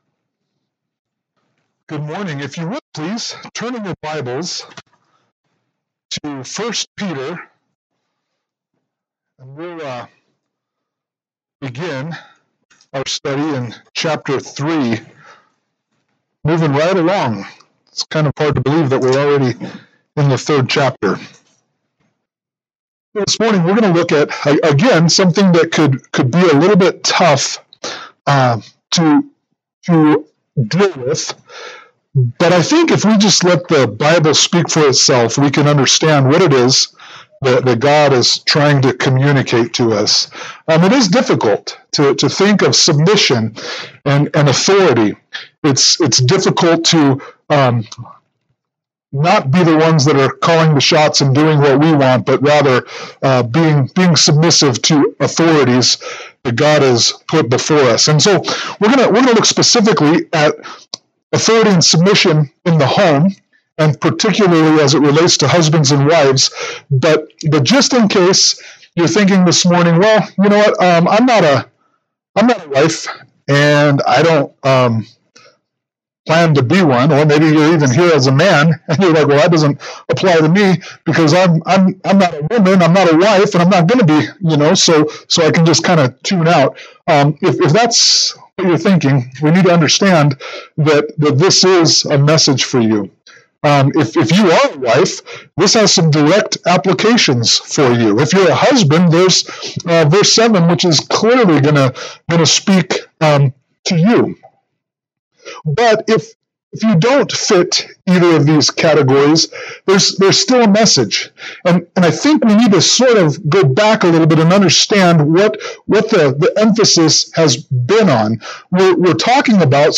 Sermon
1 Peter 3:1-7 Service Type: Sunday Morning Worship « 1 Peter 2:13-17 1 Peter 3:8-12